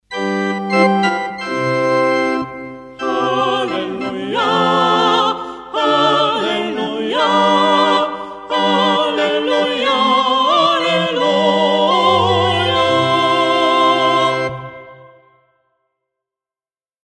Acompanhamento Musical
Fazemos o acompanhamento musical com Canto (Soprano e Tenor), Órgão, Flauta Transversal e Harpa.